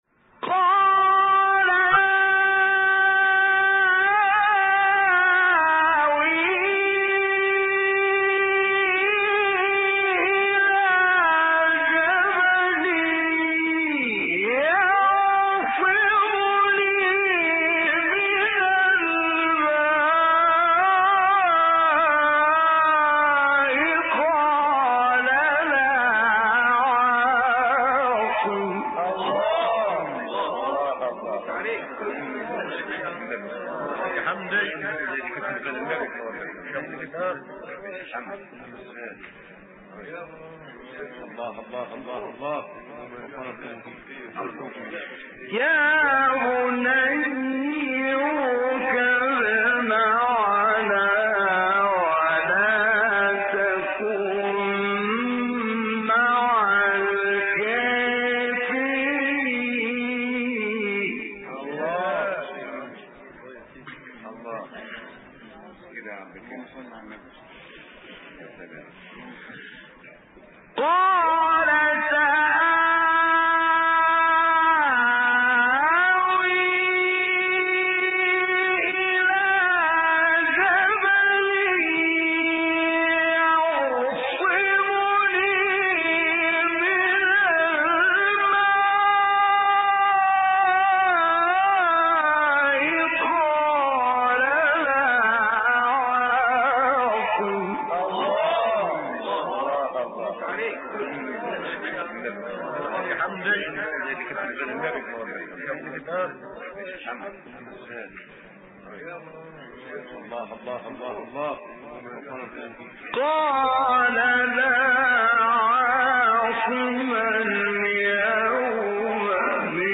مقطعی بسیار زیبا مقام سه گاه از حمدی زامل | نغمات قرآن | دانلود تلاوت قرآن